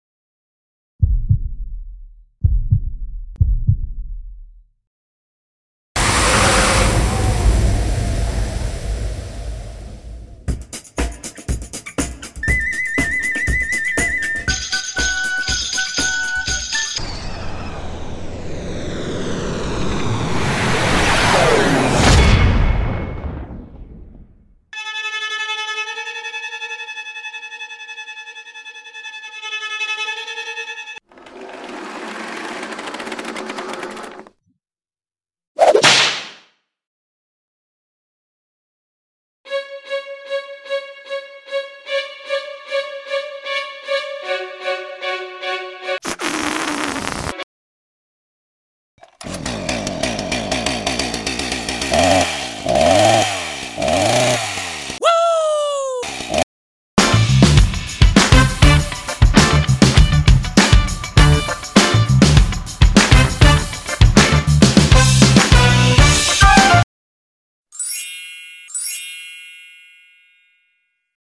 Sound Effects
MP3 file of some sound effects used in the  show.
Fairy-Tree-Sample-Soundscape-online-audio-converter.com_.mp3